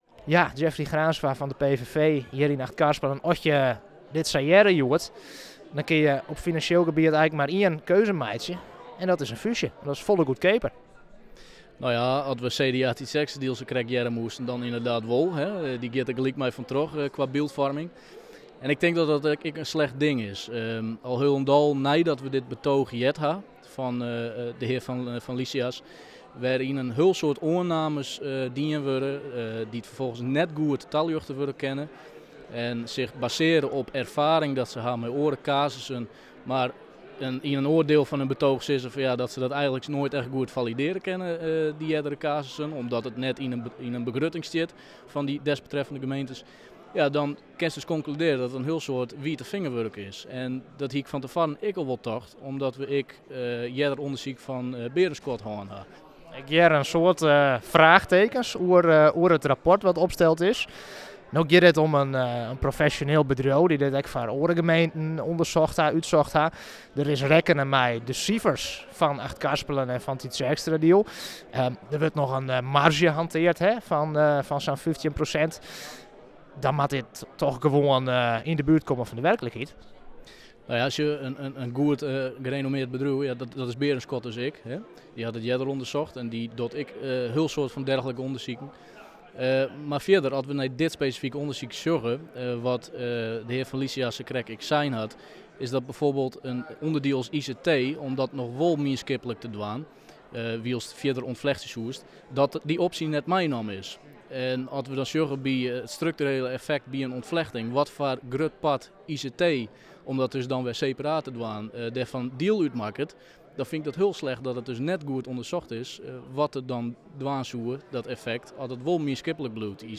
Raadslid Jeffrey Graansma van de PVV in Achtkarspelen: